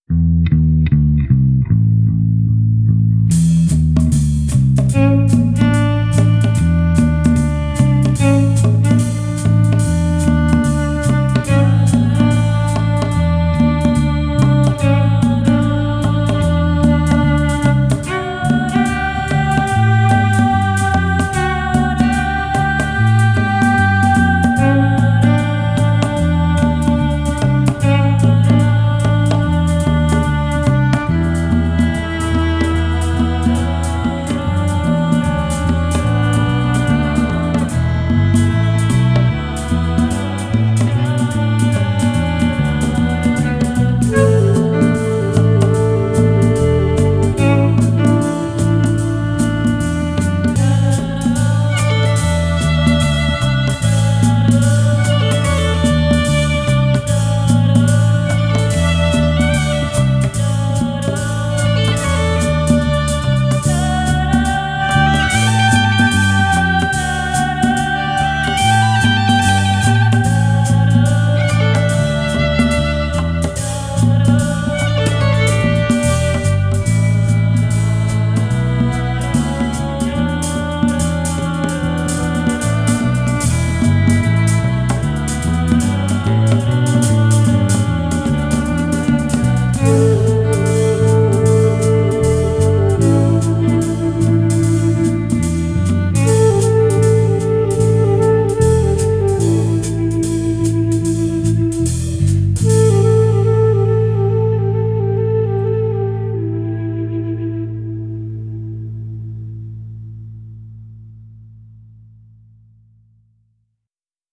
(airy jazz groove)